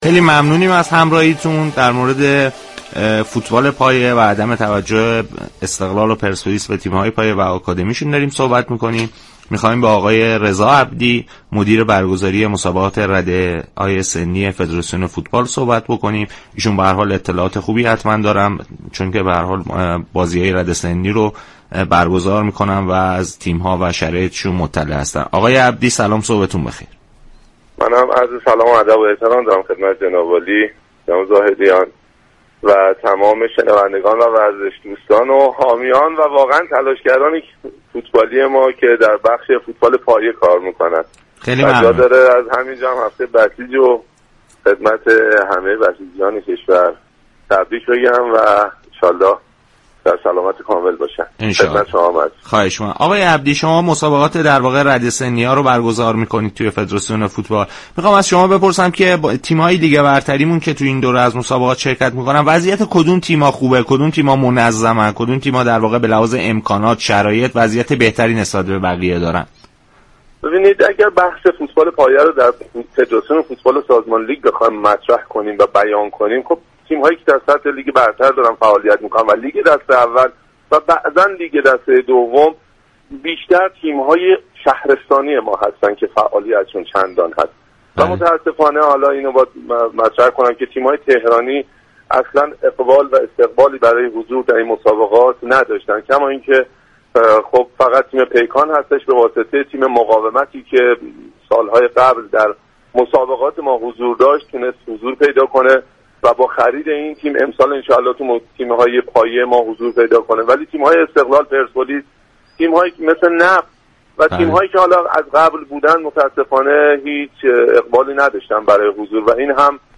در برنامه صبح و ورزش چهارشنبه 5 آذر به گفتگو درخصوص وضعیت تیم های رده سنی پایه پرداخت.